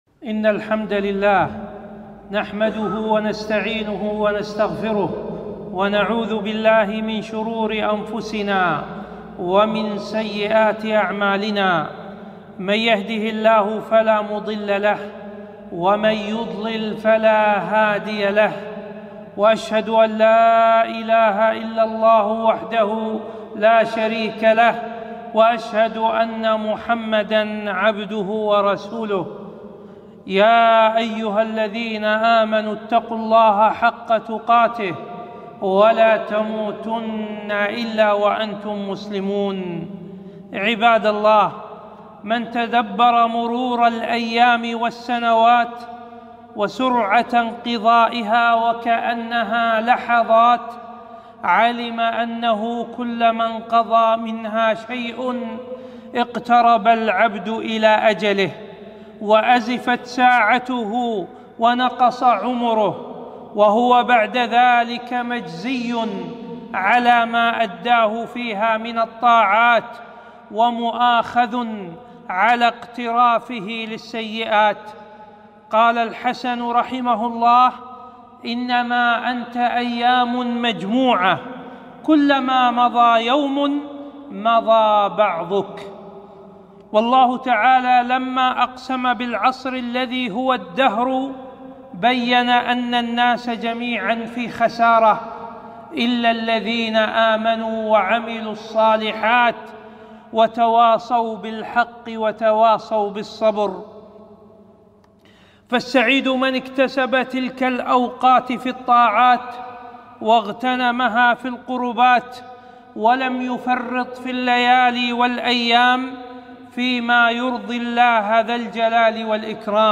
خطبة - استغلال الأوقات في عمل الطاعات